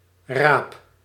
Ääntäminen
IPA: [si.tʁuj]